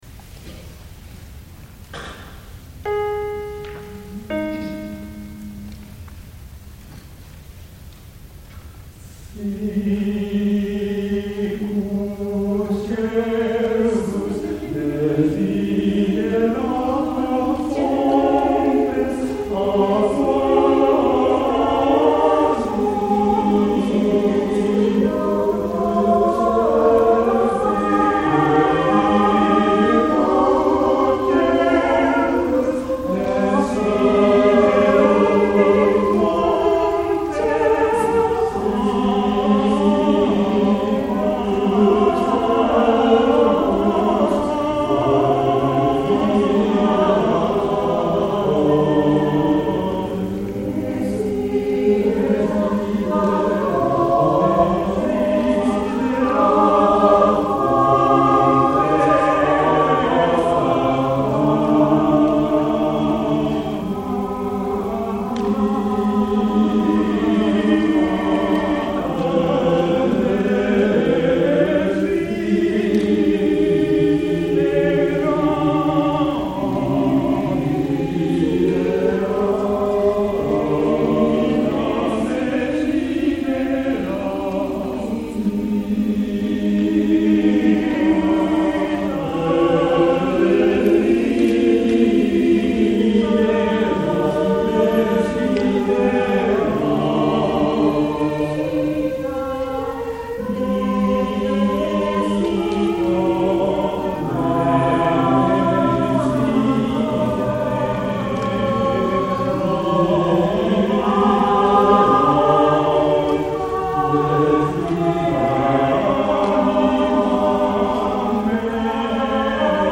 相模野混声合唱団は、中世のポリフォニーを中心にコーラスを楽しんでいる相模原市の合唱団です。